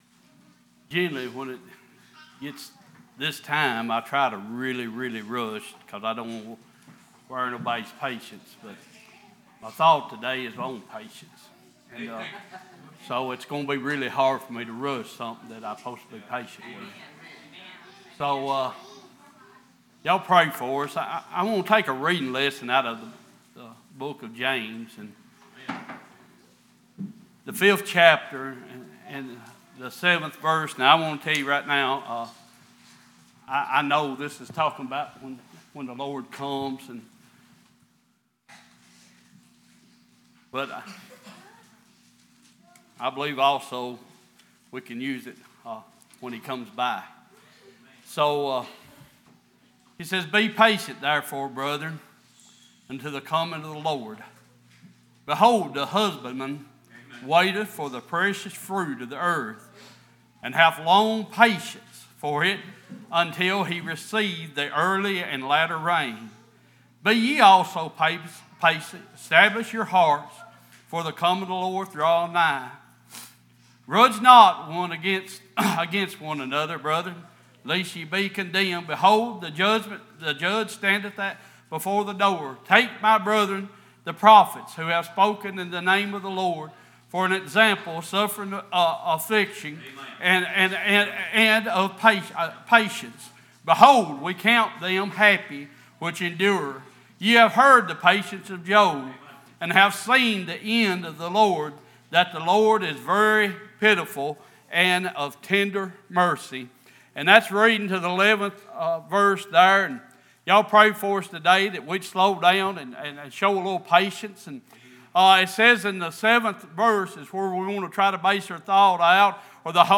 Sunday Morning Passage: James 5:7-11, Galatians 6:9, Mark 5:21-41 Service Type: Worship « Macedonian